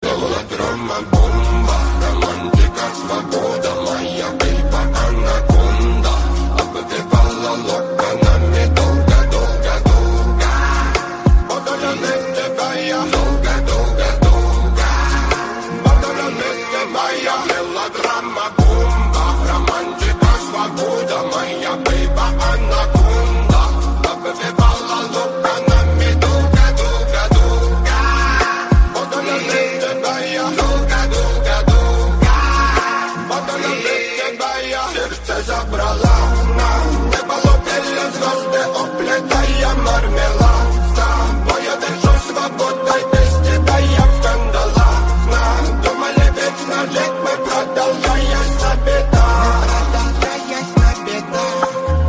• Качество: 128, Stereo
мужской голос
спокойные
расслабляющие
relax